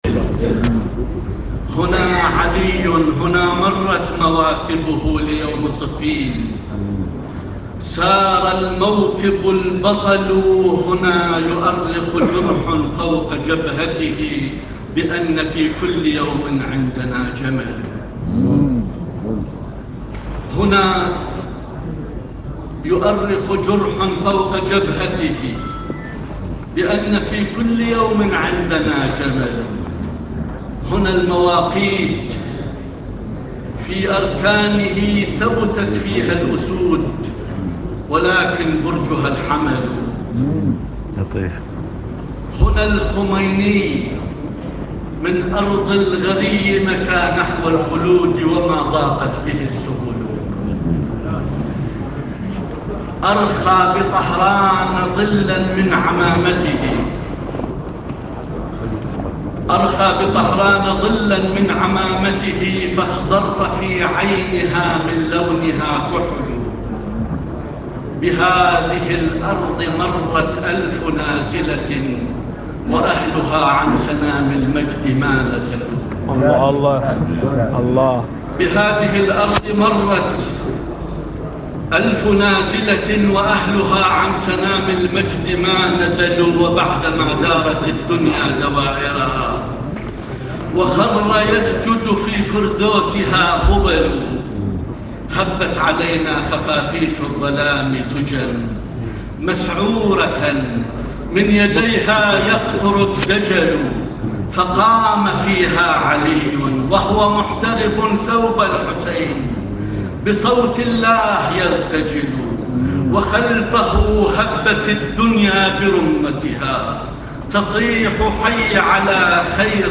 مقطع من قصيدة رائعة للسيد جابر الجابري في افتتاح مجمع الوحدة الاسلامية في بغداد
وخلال الحفل القى الشاعر العراقي الكبير والوكيل الاقدم السابق لوزارة الثقافة السيد جابر الجابري (مدين الموسوي) قصيدة رائعة نالت استحسان الحاضرين.. وهذا مقطع صوتي نها.. استمعوا اليه